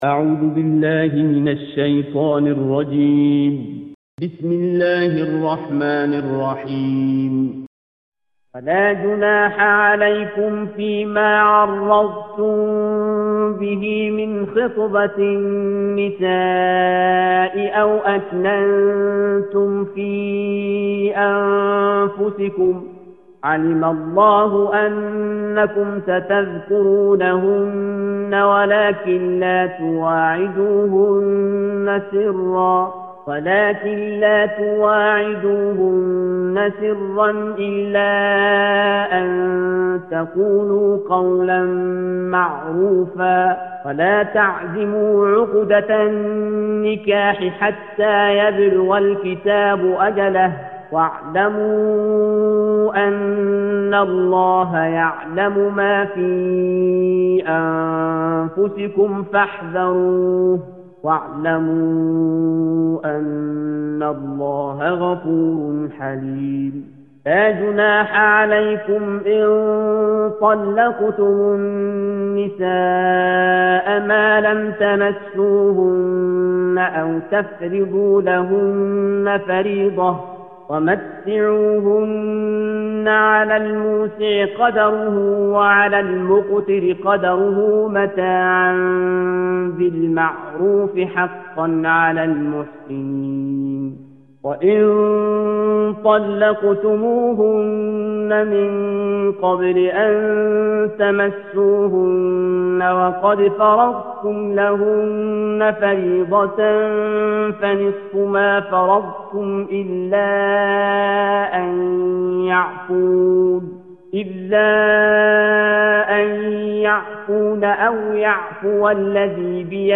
Surah Al-Baqarah - A Lecture of Tafseer ul Quran Al-Bayan by Javed Ahmed Ghamidi.